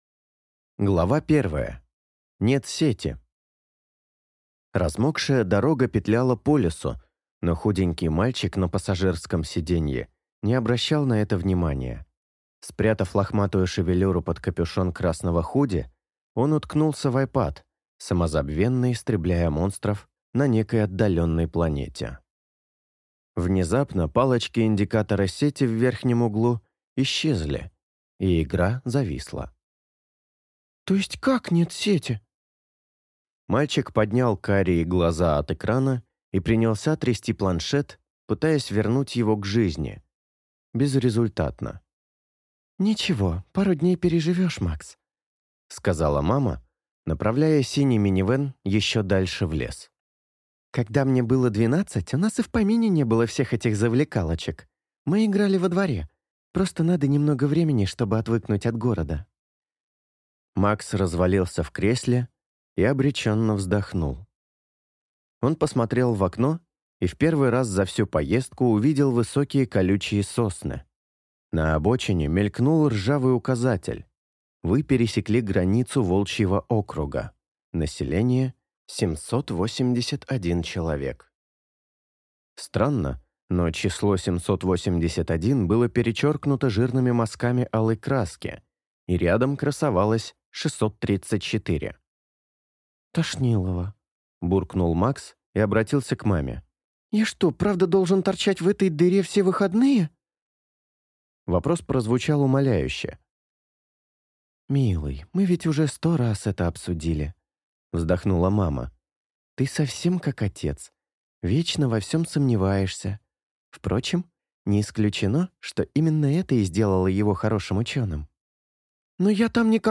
Аудиокнига Третья ночь полнолуния | Библиотека аудиокниг